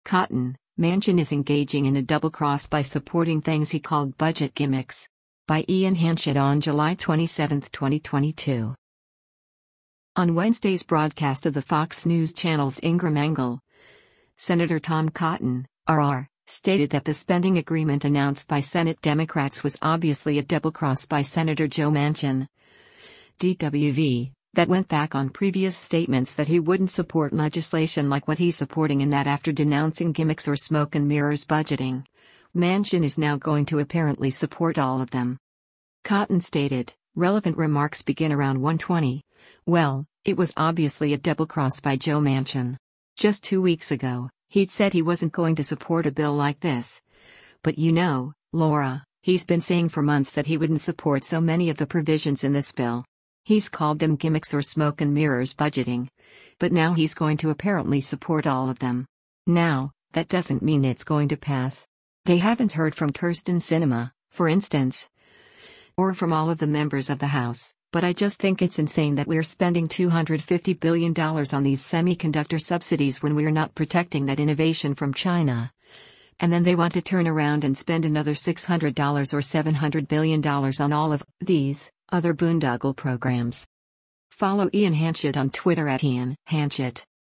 On Wednesday’s broadcast of the Fox News Channel’s “Ingraham Angle,” Sen. Tom Cotton (R-AR) stated that the spending agreement announced by Senate Democrats “was obviously a double-cross” by Sen. Joe Manchin (D-WV) that went back on previous statements that he wouldn’t support legislation like what he’s supporting and that after denouncing “gimmicks or smoke and mirrors budgeting,” Manchin is now “going to apparently support all of them.”